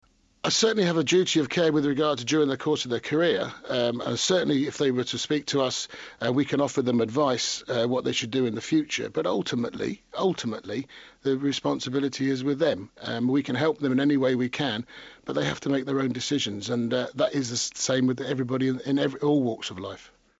Hear the whole interview on BBC Sportshour